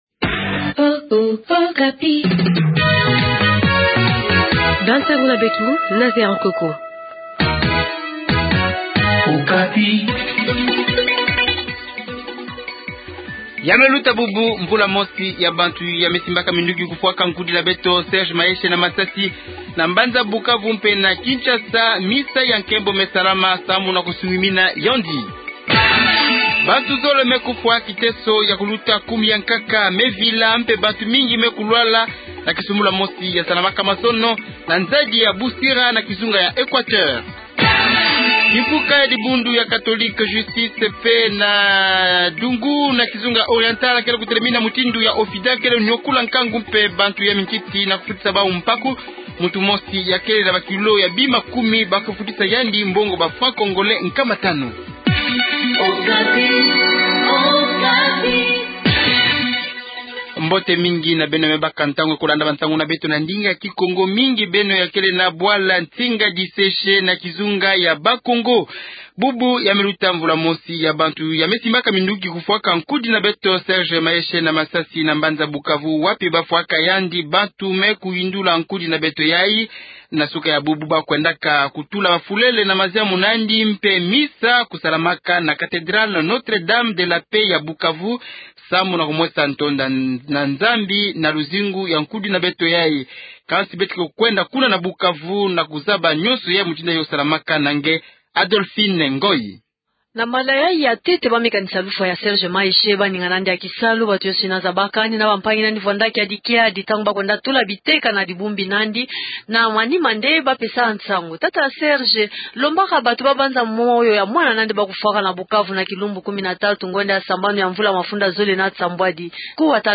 Journal Kikongo Soir